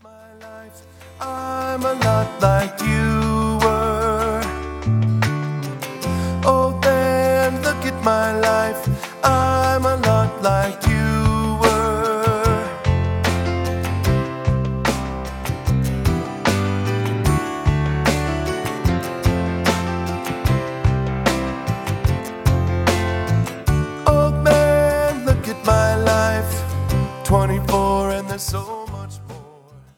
spirited and moving songs